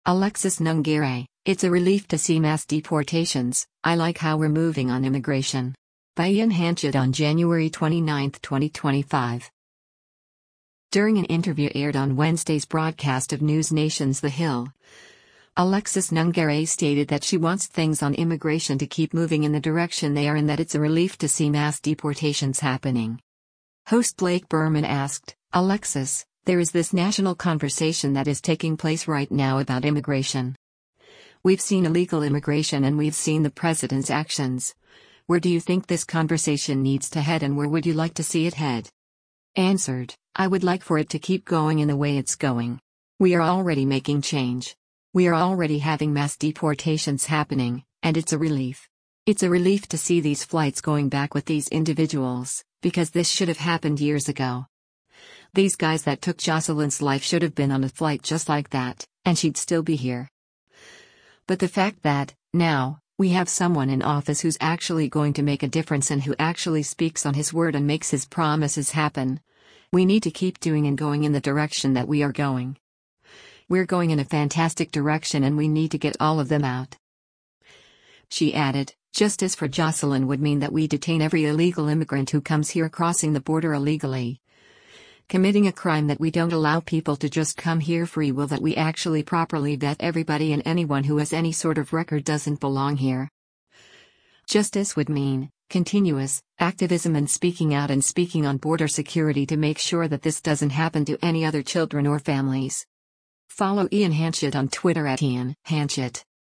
During an interview aired on Wednesday’s broadcast of NewsNation’s “The Hill,”